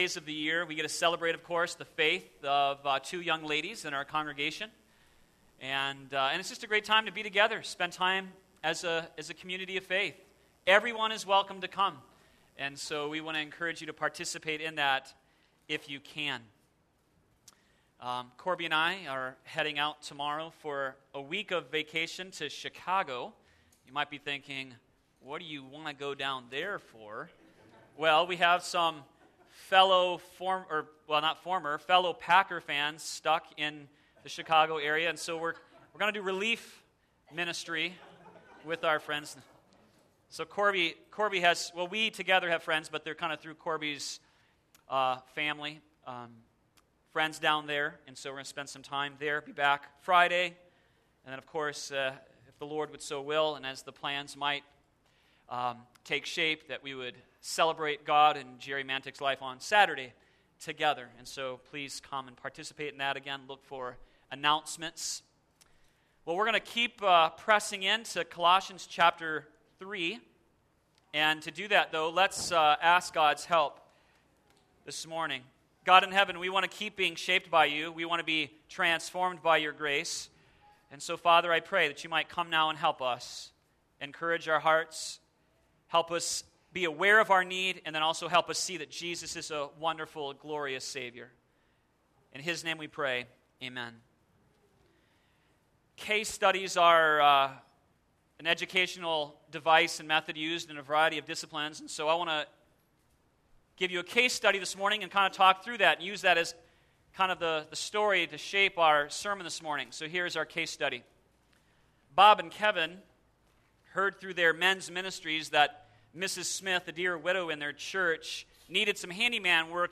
sermon7713.mp3